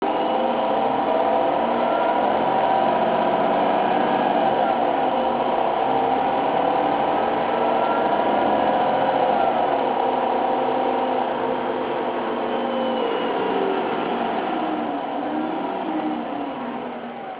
Ještě mám pro vás dva zvuky motoru ev.č. 220. Zdálo se mi, že první rychlostní stupeň tam je zařazen déle než u starších Citelisů.